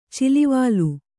♪ cilivālu